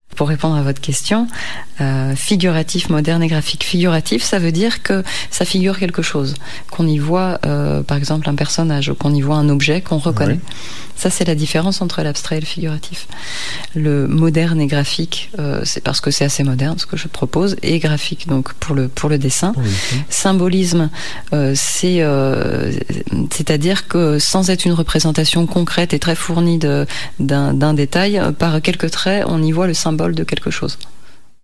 Extrait Voix
Interview